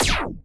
sounds_laser_01.ogg